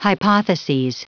Prononciation du mot hypotheses en anglais (fichier audio)